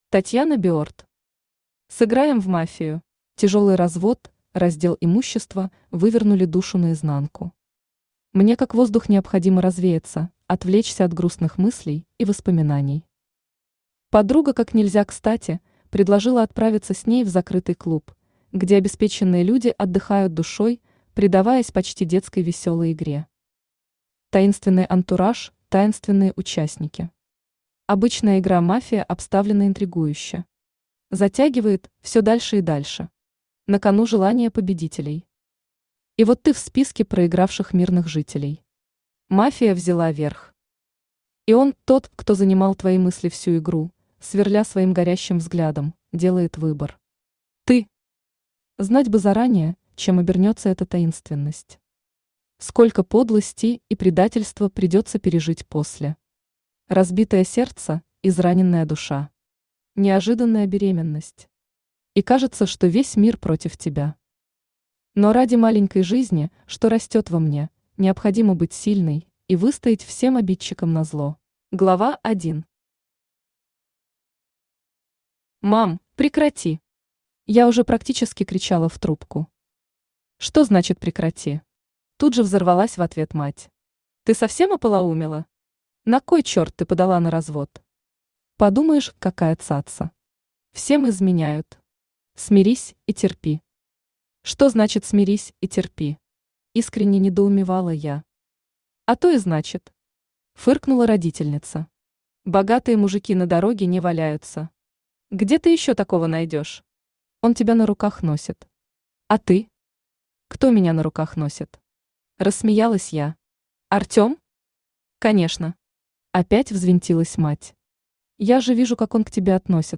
Аудиокнига Сыграем в «Мафию» | Библиотека аудиокниг
Aудиокнига Сыграем в «Мафию» Автор Татьяна Berd Читает аудиокнигу Авточтец ЛитРес.